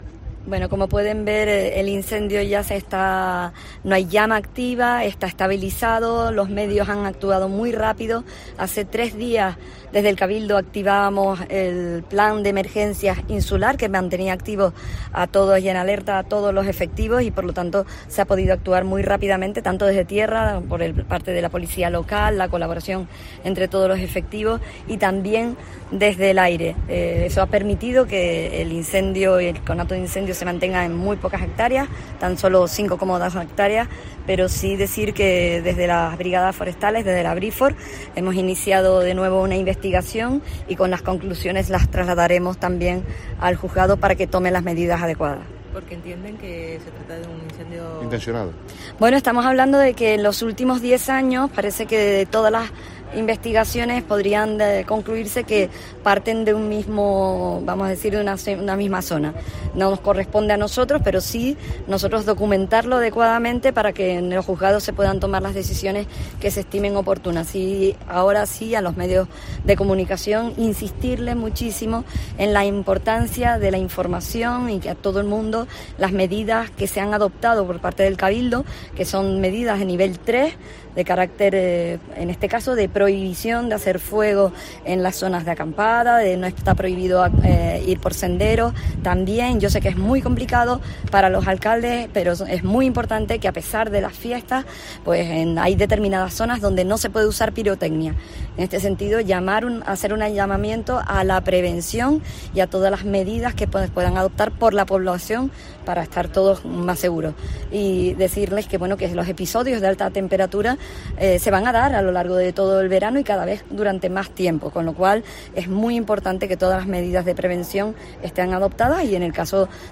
La presidenta del Cabildo y el alcalde de Los Realejos informan sobre el incendio de Icod el Alto
En COPE Canarias, el alcalde de Los Realejos, Adolfo González, ha destacado la rapidez con la que los efectivos de emergencias se han desplazado al lugar.